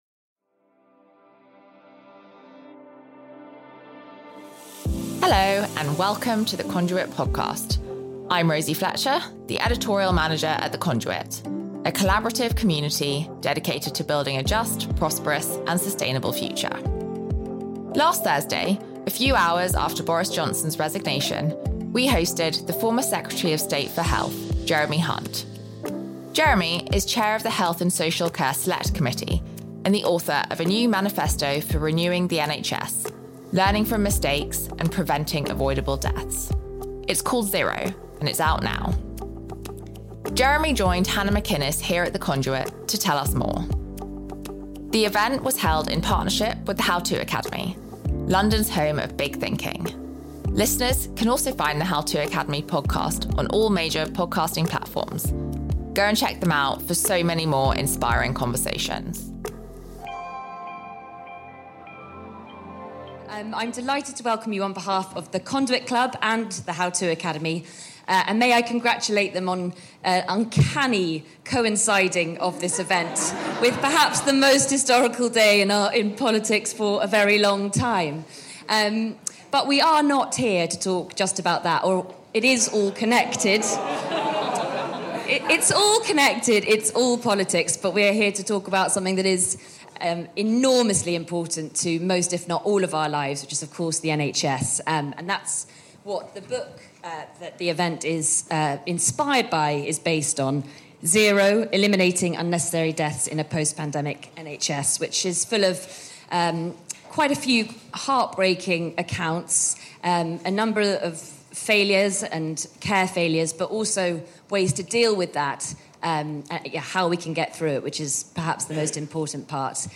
Conduit Conversations Live with Jeremy Hunt
A few hours after Boris Johnson’s resignation as Prime Minister, The Conduit welcomed to the stage the former Secretary of State for Health, Jeremy Hunt.